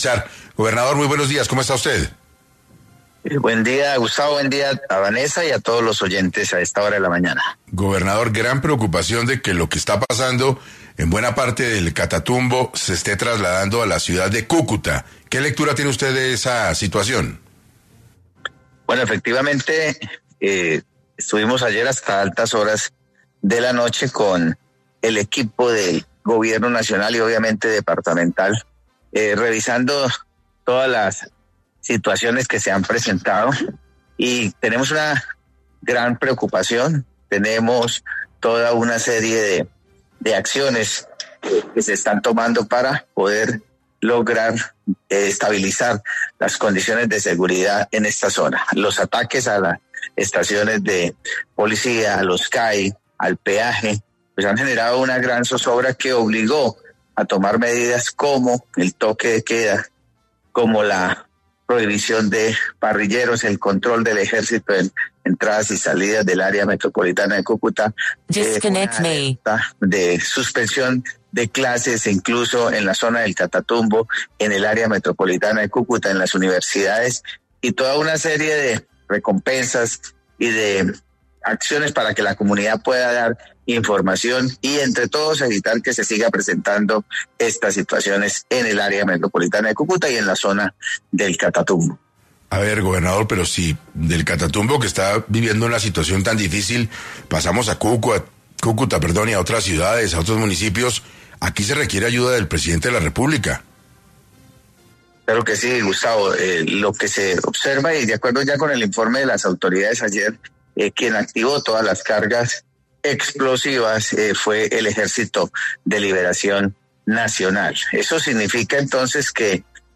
En entrevista con 6AM de Caracol Radio, William Villamizar, gobernador de Norte de Santander, confirmó que estos hechos terroristas fueron perpetrados por parte del Ejército de Liberación Nacional (ELN).